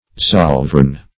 Zollverein \Zoll"ve*rein`\, n. [G., from zoll duty + verein